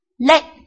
臺灣客語拼音學習網-客語聽讀拼-南四縣腔-入聲韻
拼音查詢：【南四縣腔】led ~請點選不同聲調拼音聽聽看!(例字漢字部分屬參考性質)